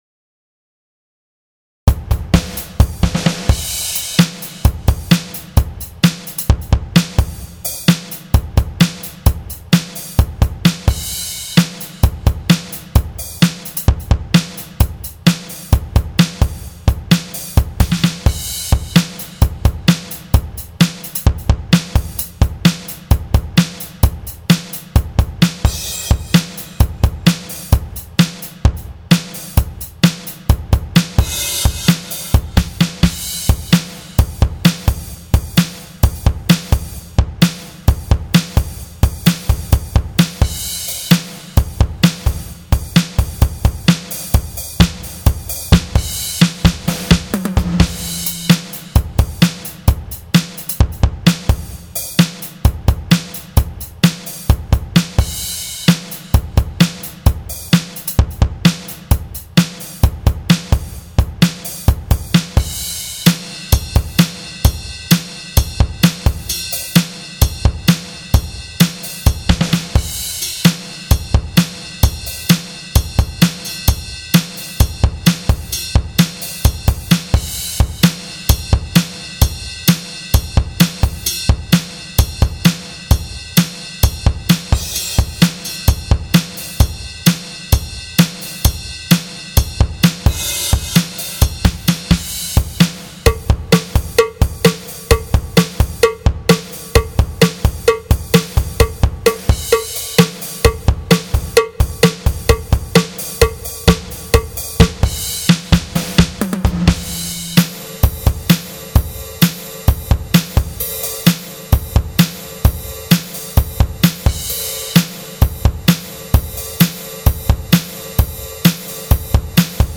Drum Grooves
Hard Rock 130.mp3